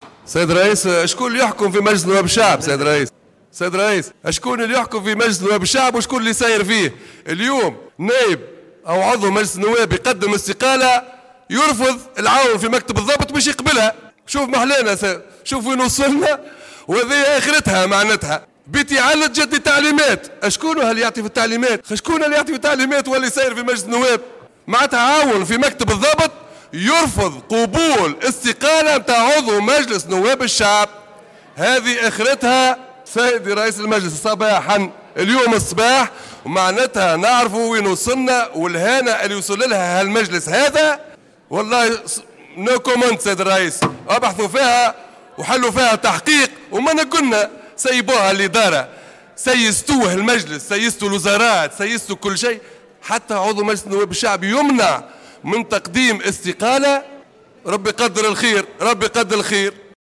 طالب النائب المستقيل من كتلة نداء تونس طارق الفتيتي خلال الجلسة العامة اليوم الخميس 10 جانفي 2018 بفتح تحقيق جدي داخل البرلمان لمعرفة أسباب رفض عون بمكتب الضبط قبول استقالة أحد النواب.